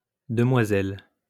Mademoiselle (pronounced [madmwazɛl] ) or demoiselle (pronounced [dəmwazɛl]